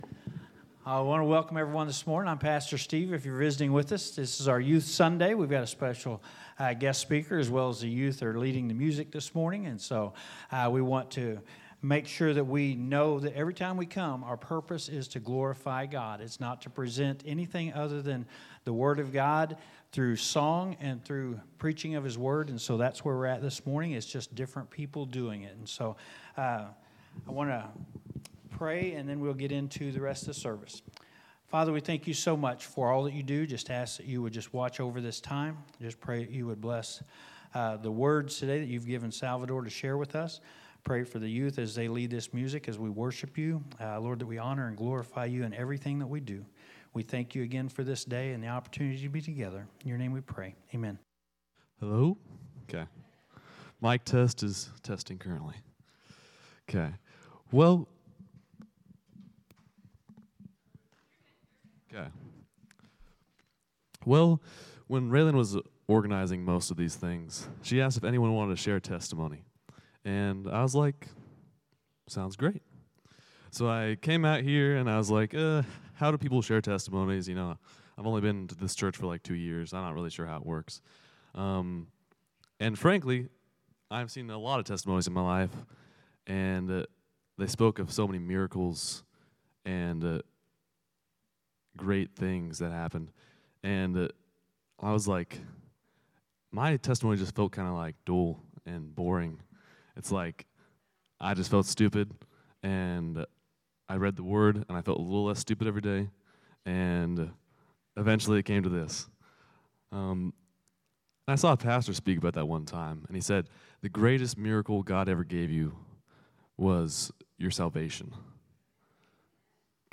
Youth Sunday Message